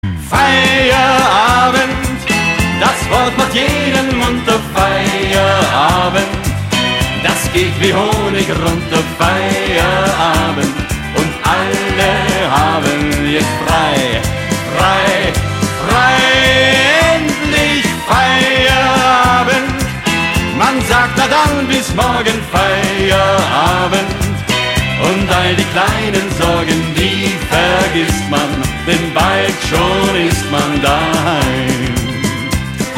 Gattung: Moderner Einzeltitel
Besetzung: Blasorchester
Tonart: B-Dur Artikel-Nr.